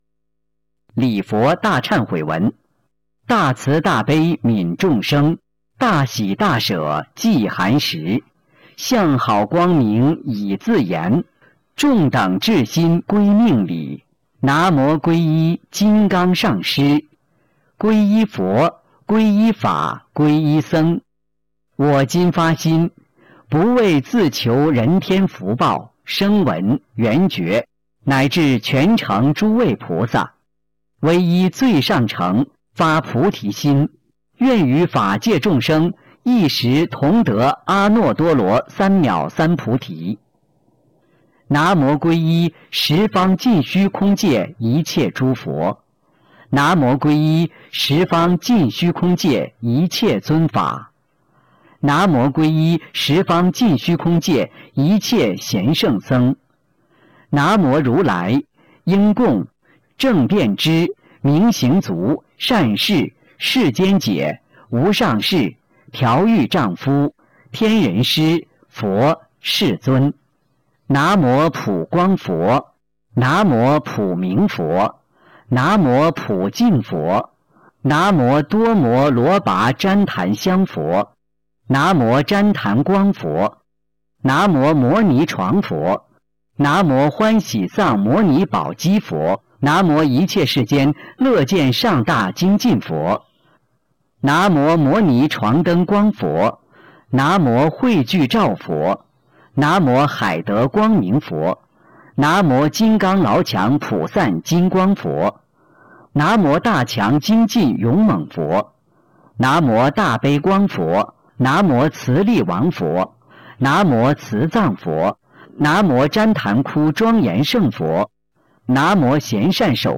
004《礼佛大忏悔文》教念llfn